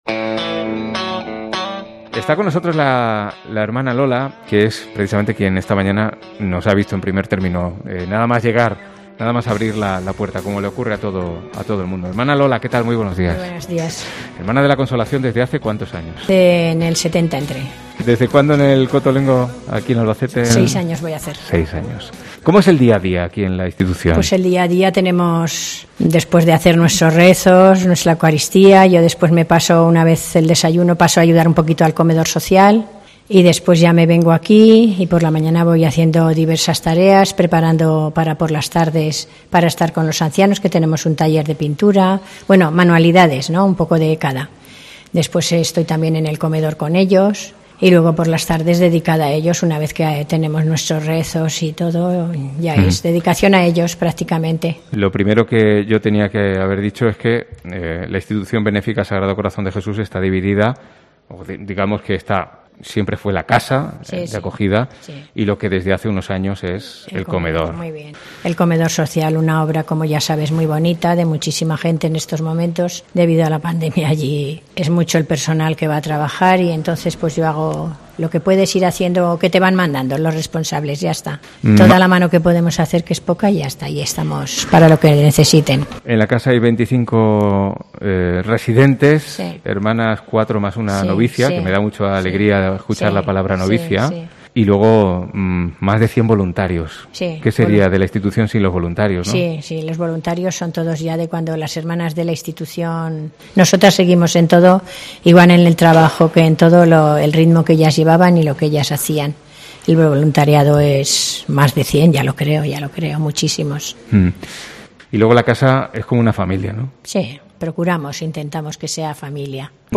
Dos fantásticos testimonios de nuestra visita al Cotolengo